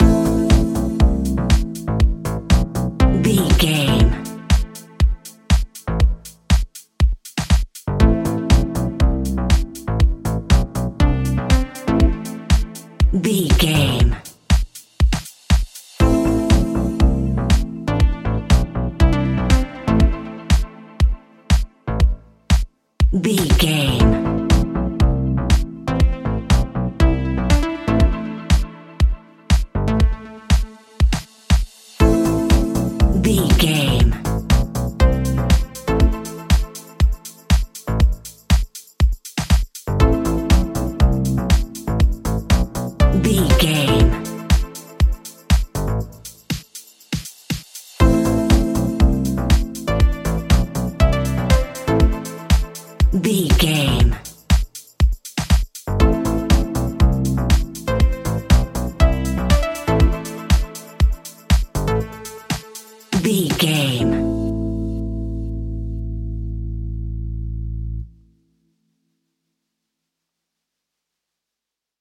Aeolian/Minor
groovy
uplifting
driving
energetic
drums
bass guitar
synthesiser
electric piano
funky house
deep house
nu disco
upbeat
instrumentals